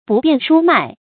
注音：ㄅㄨˋ ㄅㄧㄢˋ ㄕㄨ ㄇㄞˋ
不辨菽麥的讀法